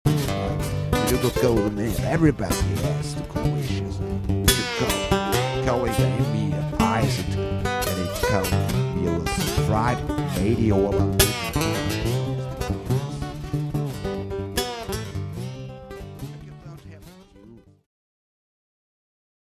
Aufgenommen am 12.12.04 im Porgy & Bess Wien